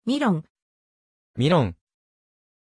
Aussprache von Milàn
pronunciation-milàn-ja.mp3